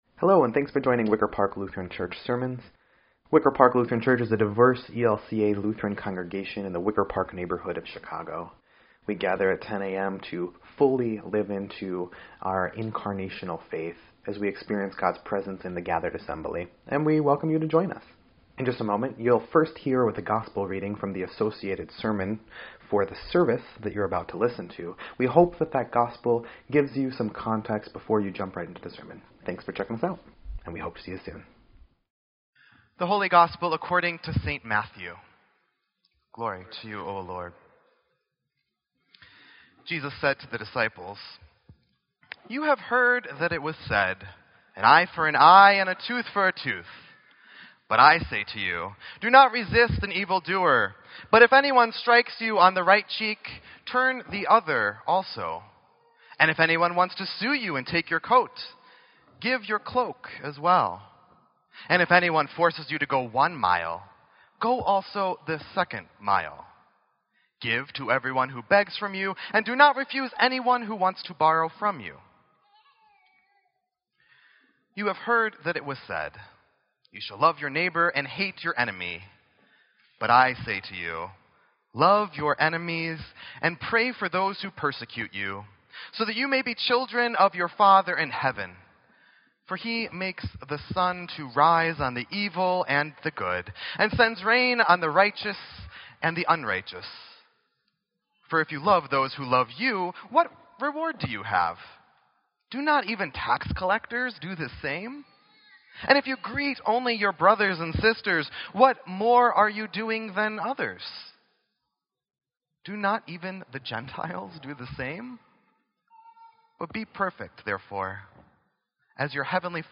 Sermon_2_19_17_EDIT.mp3